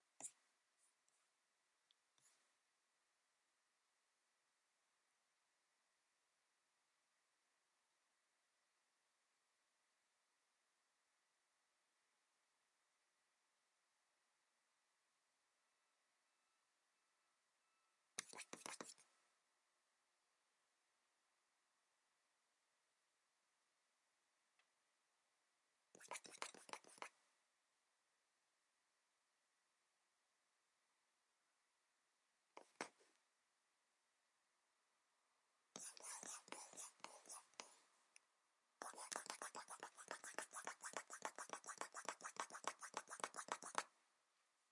描述：走在沙漠的猫。
Tag: 走路